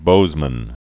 (bōzmən)